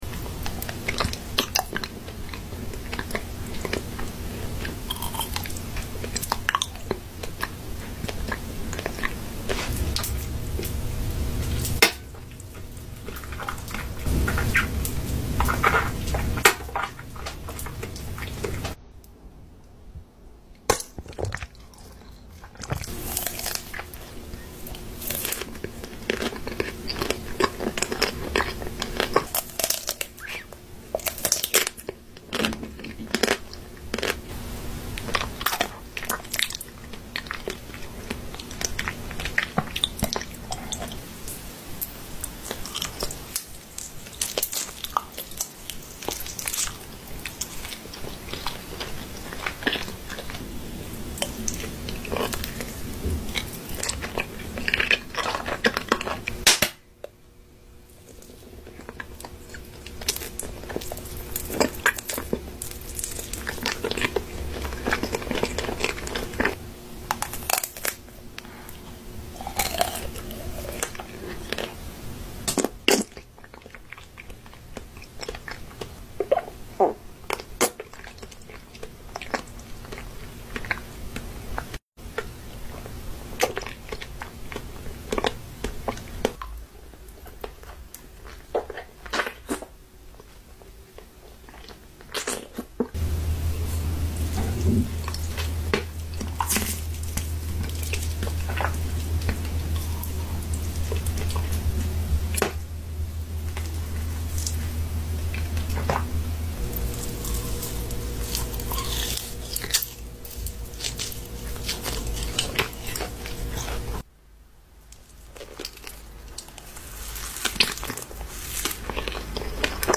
SLEEP SOUND asmr eating